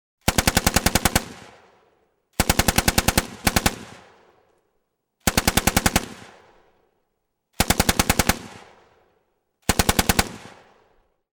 Tiếng súng Máy bắn
Download tiếng súng máy mp3 chất lượng cao, tải file hiệu ứng âm thanh tiếng súng bắn liên thanh mp3 hay nhất, tải miễn phí tiếng súng bắn liên thanh chất lượng cao, tiếng súng máy...